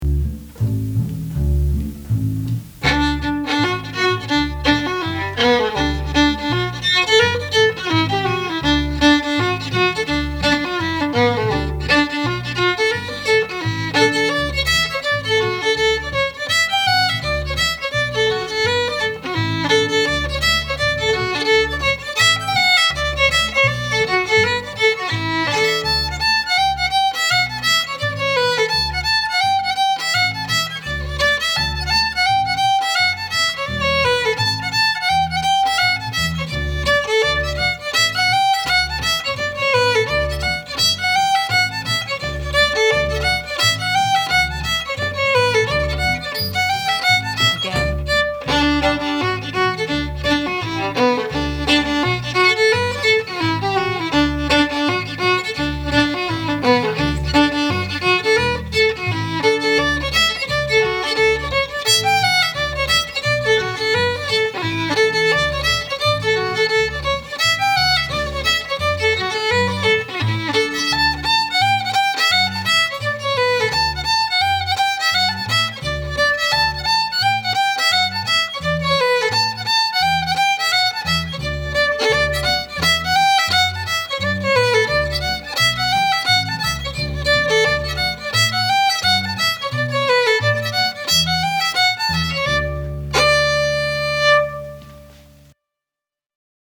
Reel - D Major